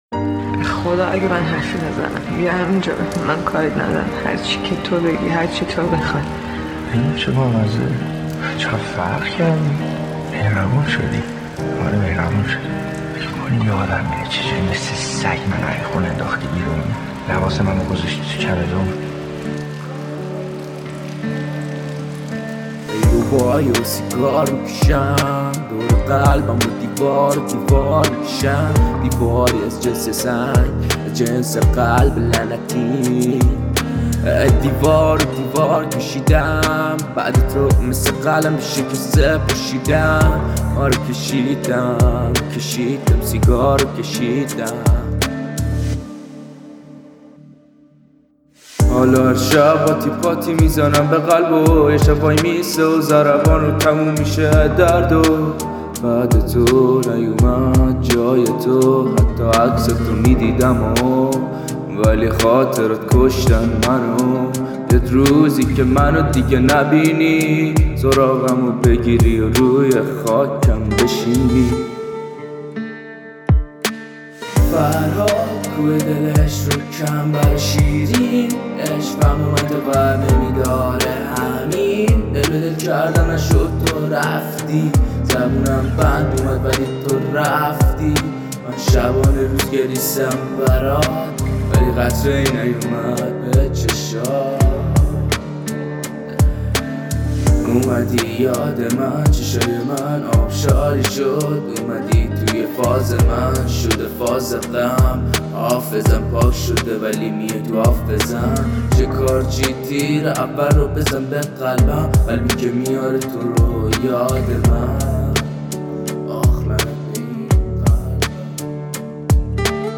/ فیلم برچسب‌ها: موزیک دانلود موزیک دانلود آهنگ جدید آهنگ غمگین دانلود آهنگ غمگین دانلود موزیک آهنگ غم انگیز دیدگاه‌ها (اولین دیدگاه را بنویسید) برای ارسال دیدگاه وارد شوید.